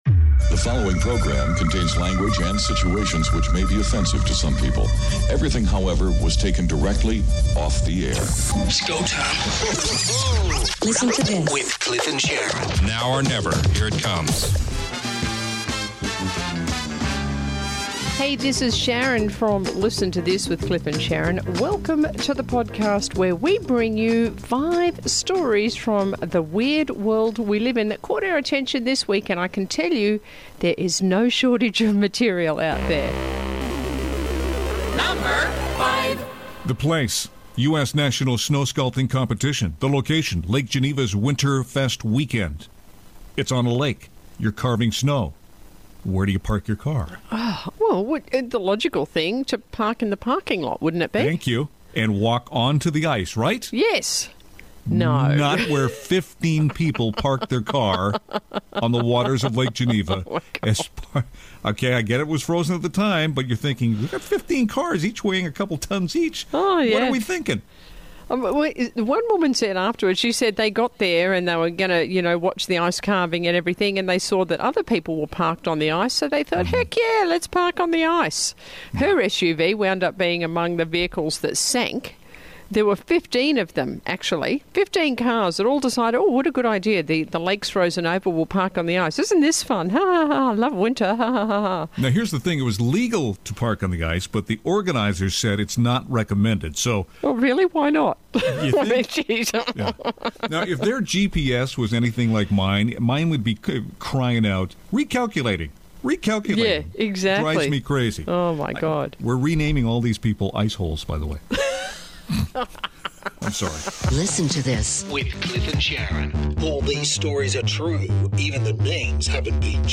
We talk with one of the inventors. Also, meet some really dumb people...like the ones who decided to park on a frozen lake...you just know how that's going to end, or the woman who really thinks she's a cat...hmm, or how about Siri's take on Spandex and her new incarnation for it, plus a heartwarming story about a teenager getting a new heart.